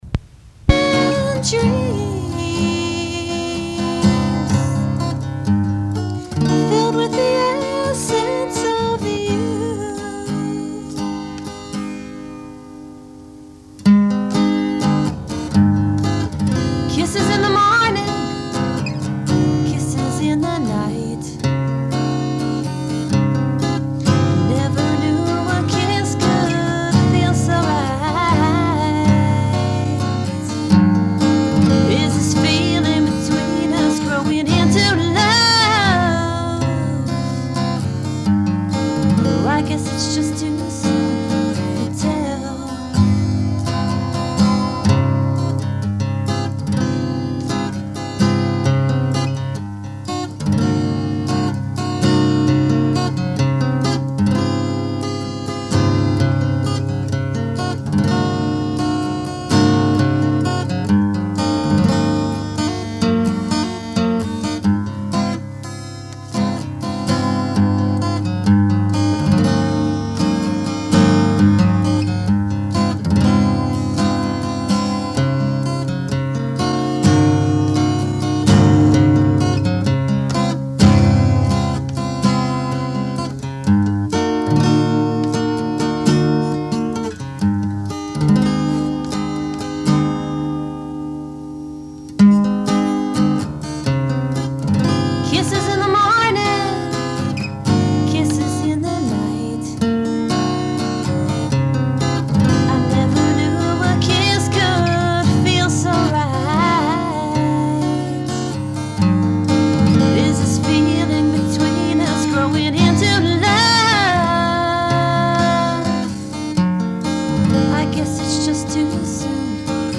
Recorded 1980 - practice tape